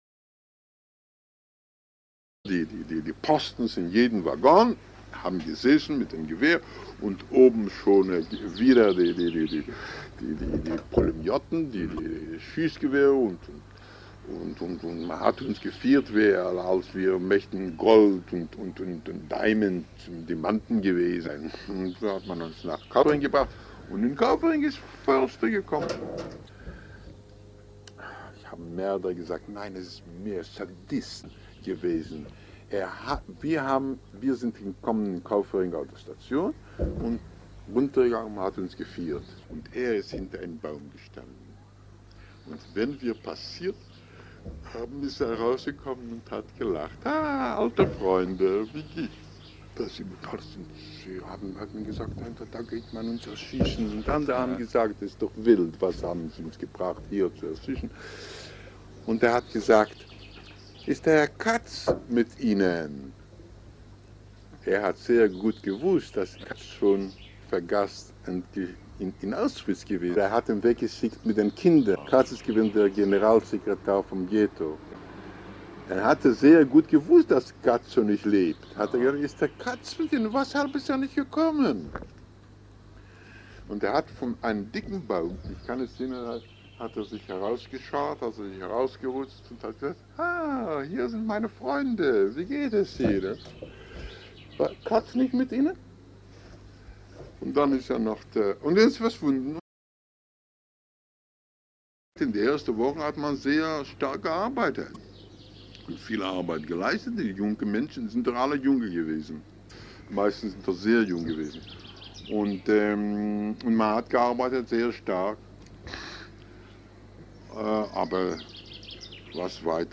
Auszug aus einem Interview am 9. Juni 1984 aufgenommen in Zürich Inhalt: Erlebnisse KZ-Lager Kaufering II und Auflösung der KZ-Lager Ende April 1945